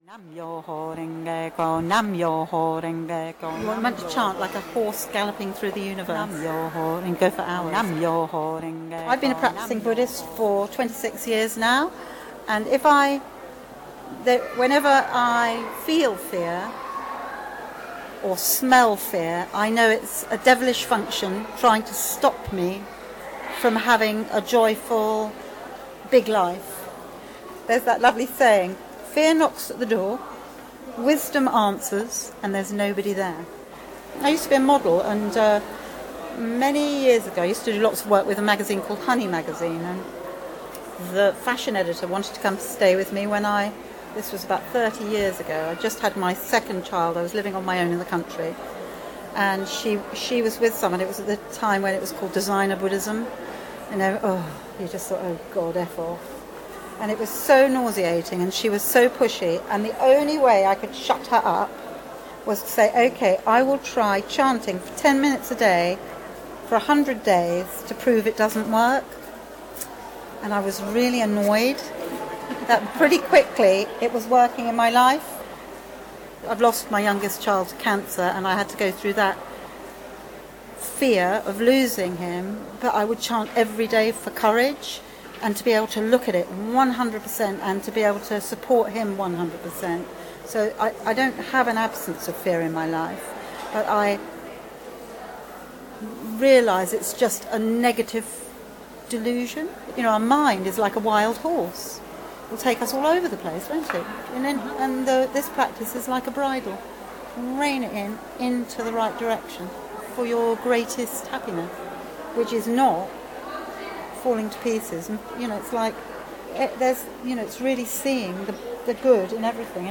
The assignment was to ask a stranger about fear- I couldn't have hope to meet a wiser and more entertaining interviewee. This lady talks with great eloquence about loss, fear and her Bhuddist faith.